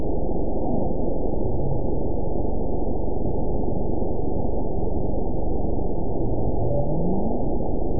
event 912425 date 03/26/22 time 15:47:25 GMT (3 years, 1 month ago) score 9.53 location TSS-AB05 detected by nrw target species NRW annotations +NRW Spectrogram: Frequency (kHz) vs. Time (s) audio not available .wav